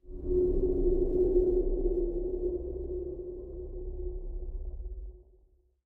ambient_drone_1.ogg